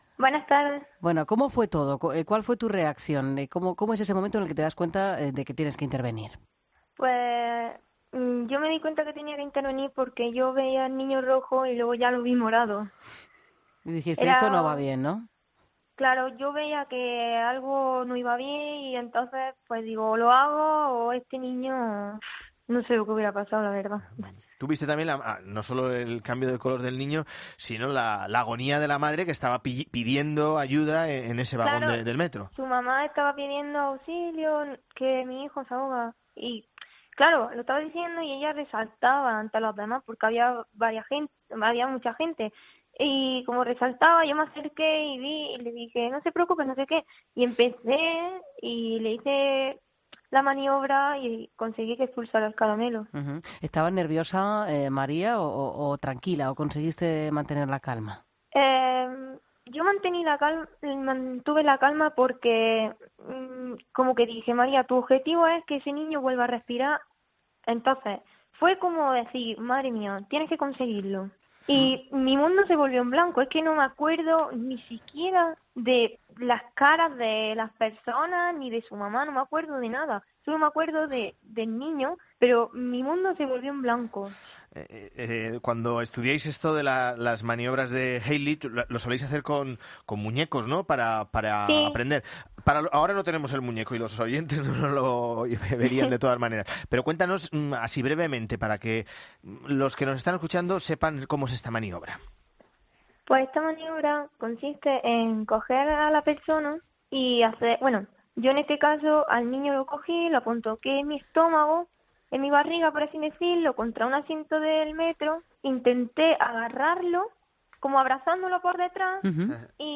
Veía que algo no va bien y me dije o hago algo o no sé que hubiera pasado" cuenta en 'Mediodía COPE' con una voz tan dulce como firme y decidida.